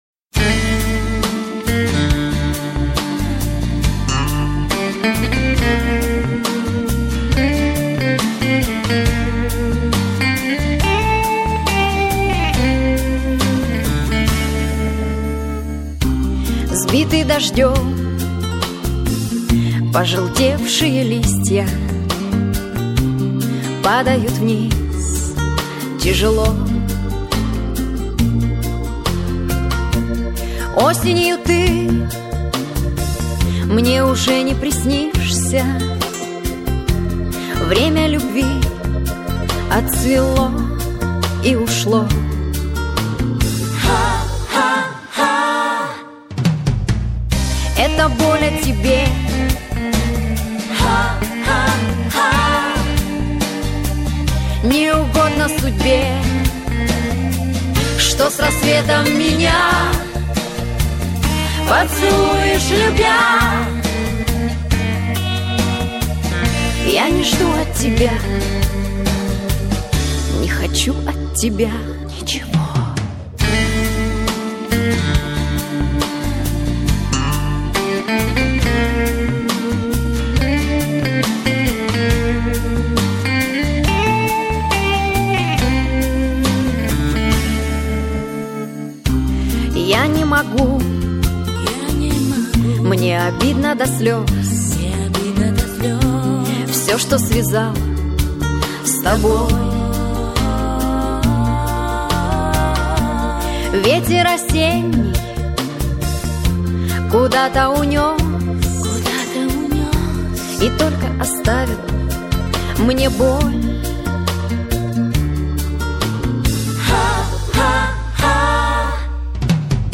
Красиво очень.. и песня.. с грустинкой... проникновенно очень...